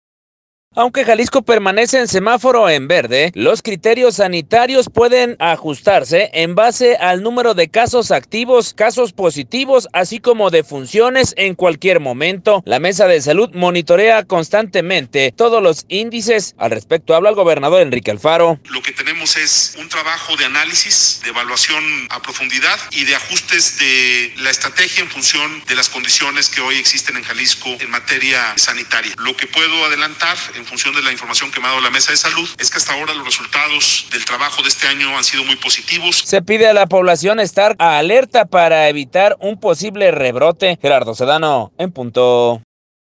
Aunque Jalisco permanece en semáforo en verde, los criterios sanitarios pueden ajustarse, con base al número de casos activos, casos positivos, así como defunciones en cualquier momento, la Mesa de Salud, monitorea constantemente todos los índices. Al respecto habla el gobernador, Enrique Alfaro: